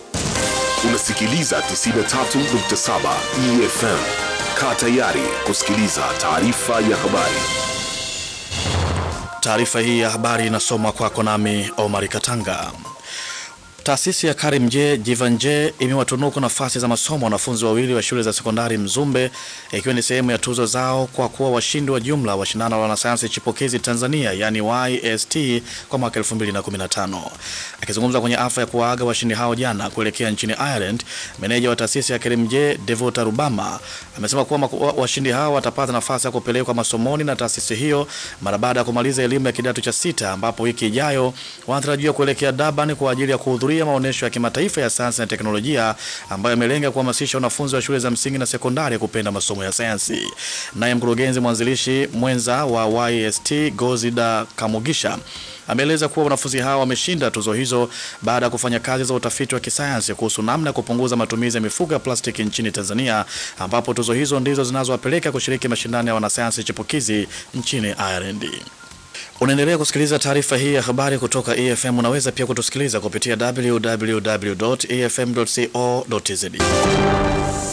Press Conference Courtyard 2016
A press conference to bid farewell to YST 2015 to visit BTYSTE in Dublin was held at the Courtyard on 5th January 2016 and the following media was present to record the event.